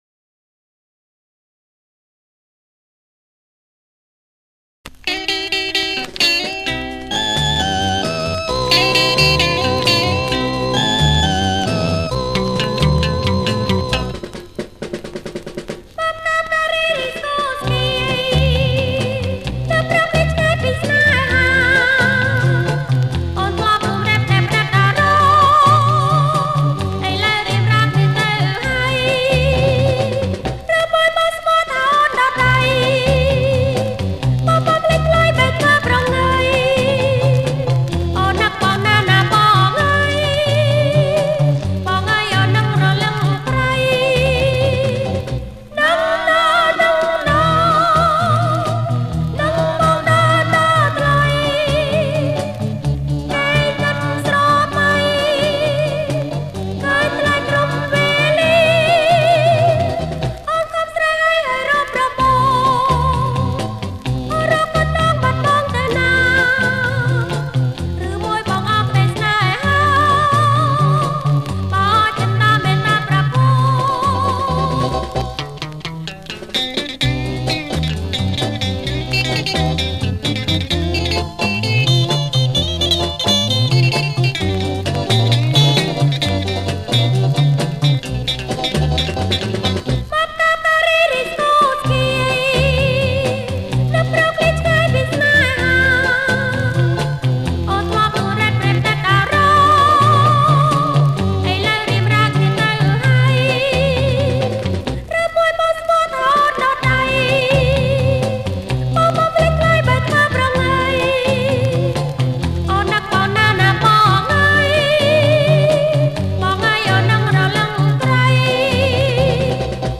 ថតផ្ទាល់ពីថាស